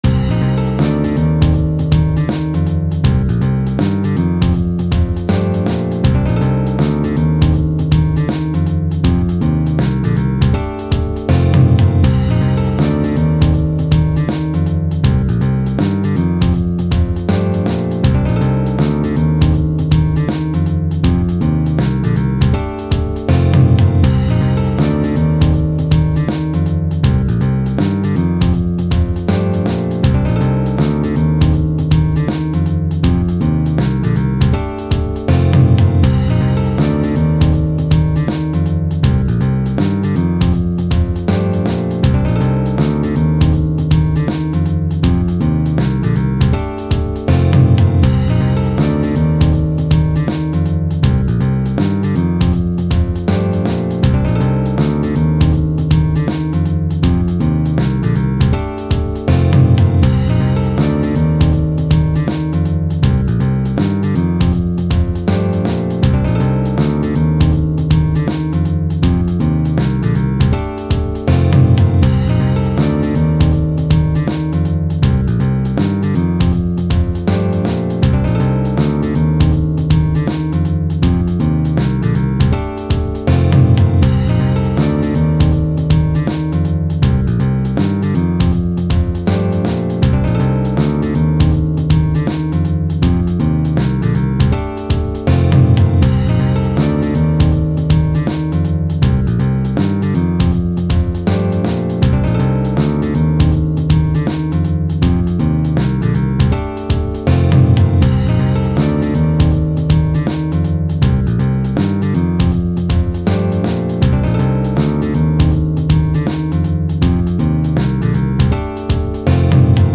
Playback en MP3 :